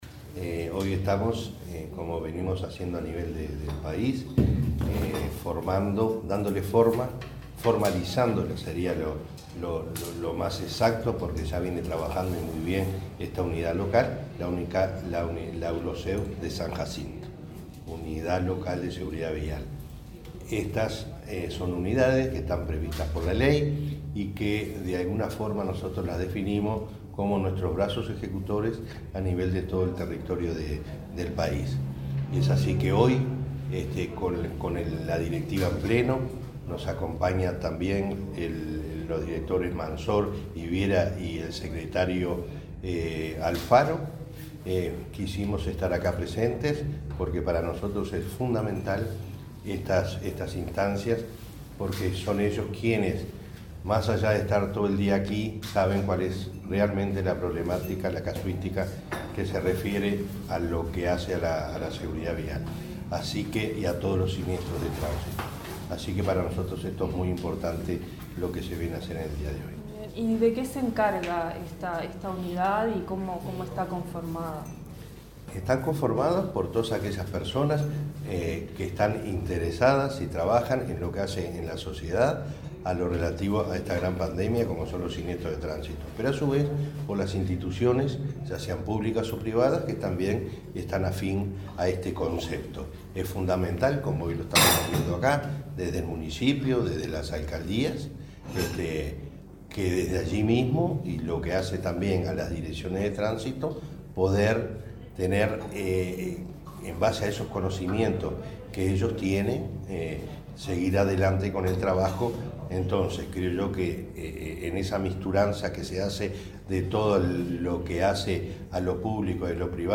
Entrevista al presidente de la Unasev